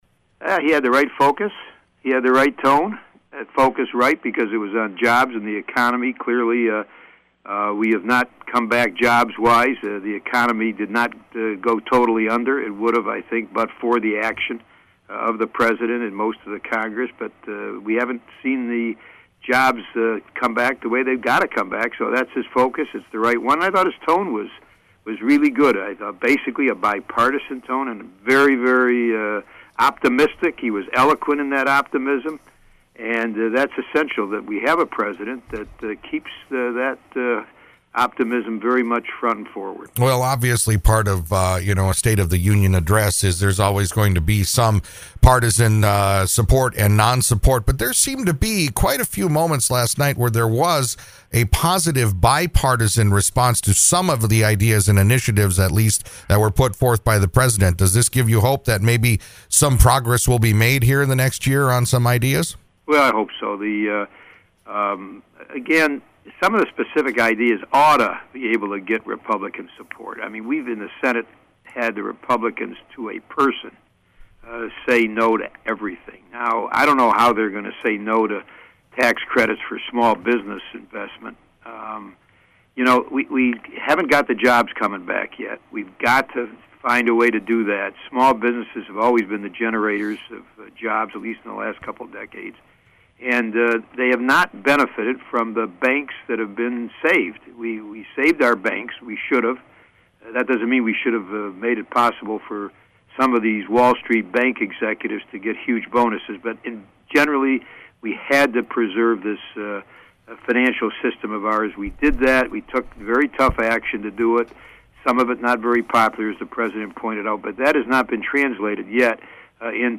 I spoke with Michigan’s Senior U.S. Senator, Carl Levin the morning after President Barack Obama delivered his State of the Union address last week.